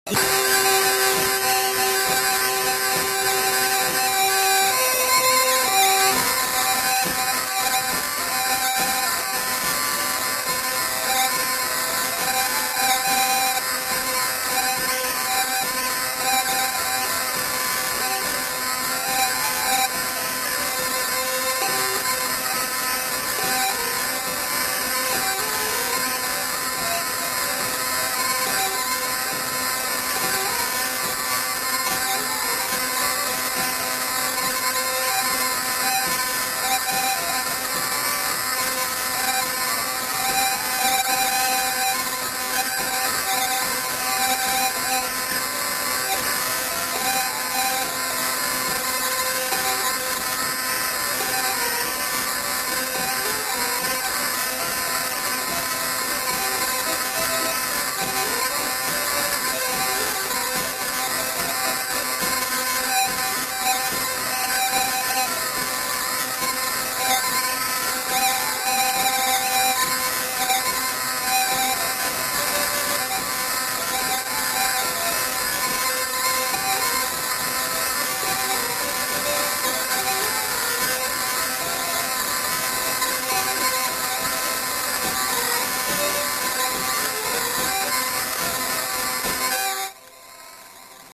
Valse
Aire culturelle : Gabardan
Lieu : Oeyreluy
Genre : morceau instrumental
Instrument de musique : vielle à roue
Danse : valse